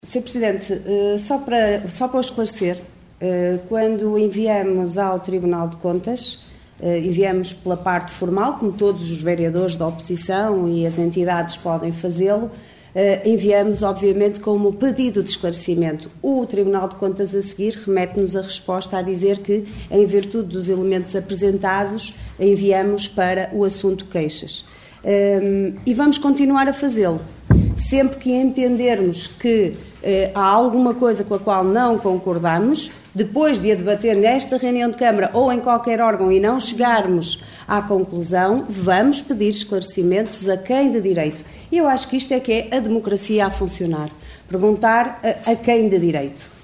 Excertos da última reunião do executivo.